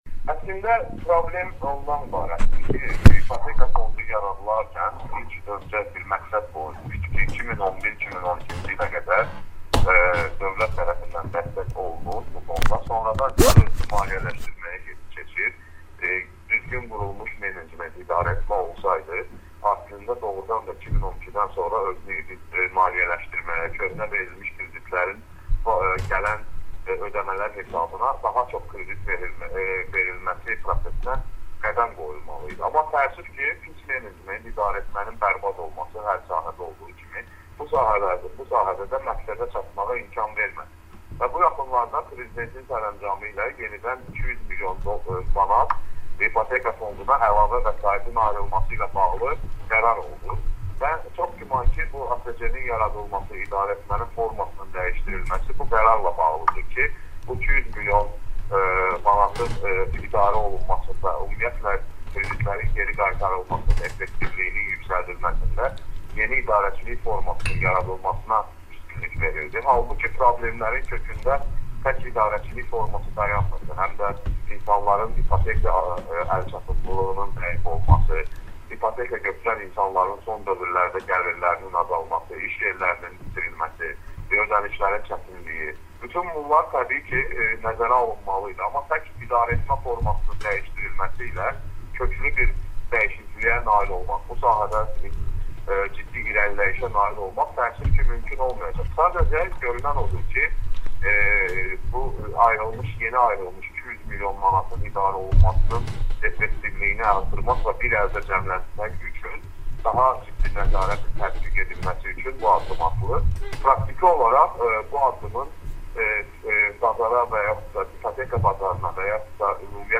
Radio: AzadliqLive